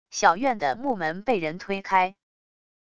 小院的木门被人推开wav音频